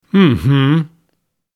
Cynical Mhmm Sound Effect
A sarcastic “mhmm” vocal sound, spoken with closed lips, adds attitude and irony to your project.
Cynical-mhmm-sound-effect.mp3